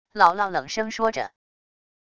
姥姥冷声说着wav音频生成系统WAV Audio Player